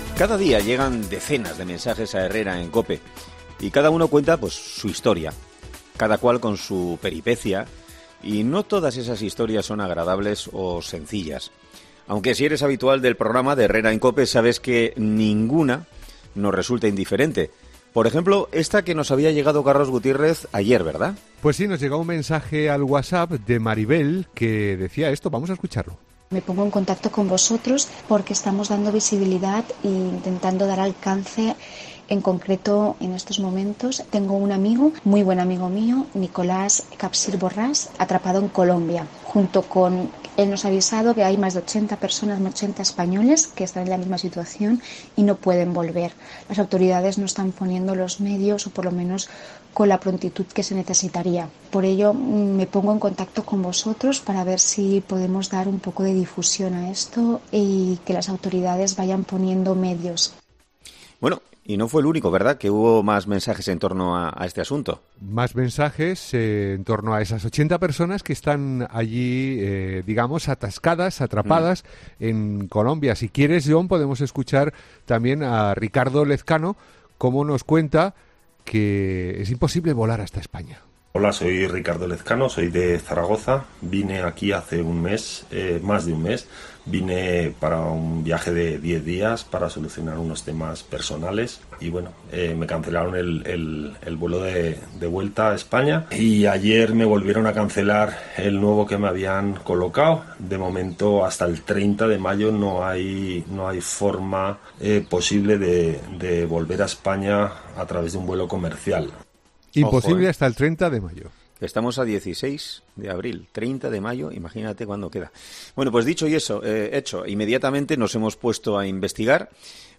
español confinado a la fuerza en Colombia